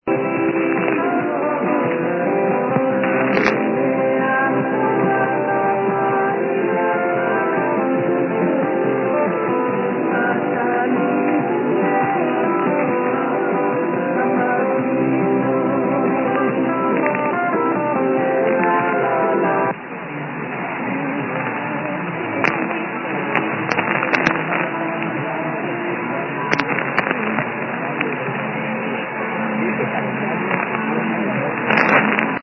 What an awesome signal.